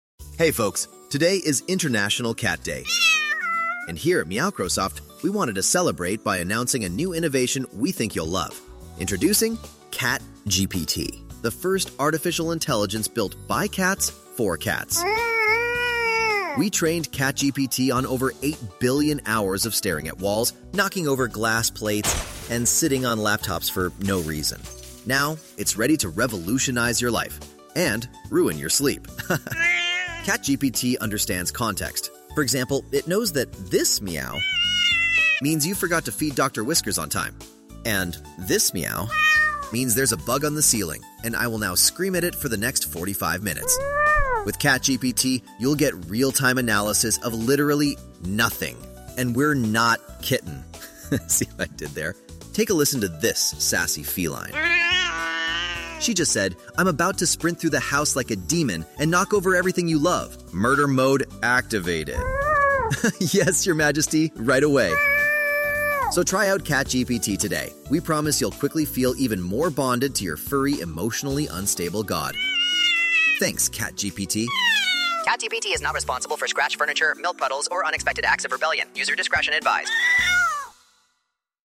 cat-day-comedy-bit-introducing-catgpt.mp3